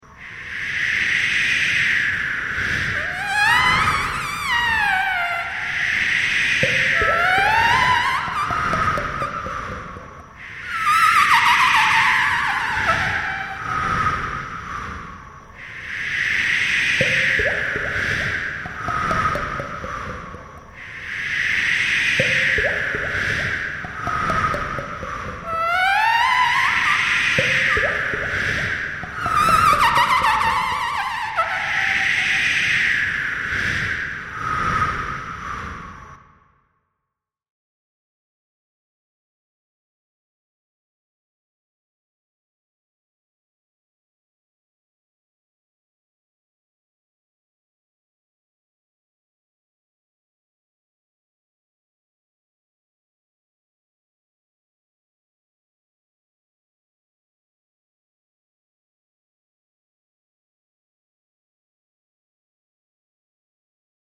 Ambiente-Marino-1-1.mp3